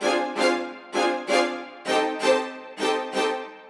Index of /musicradar/gangster-sting-samples/130bpm Loops
GS_Viols_130-DA.wav